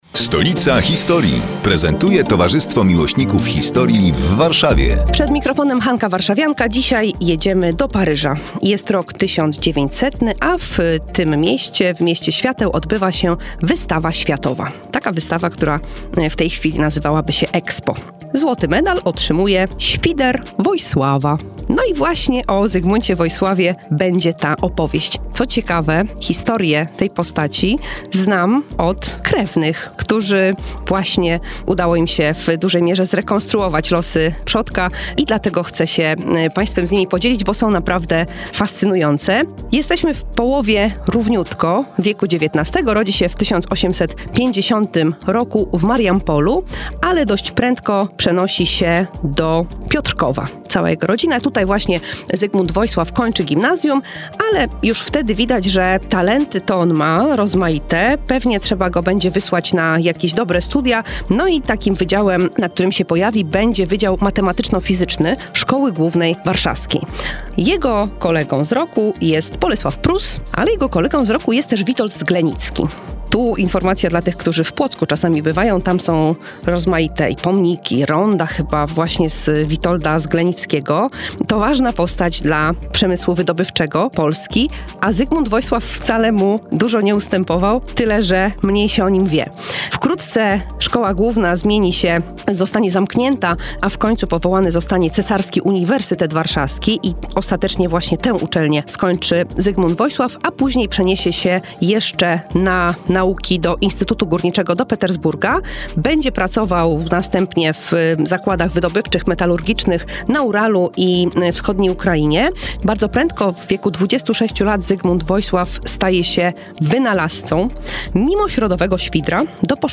Kolejny felieton pod wspólną nazwą: Stolica historii.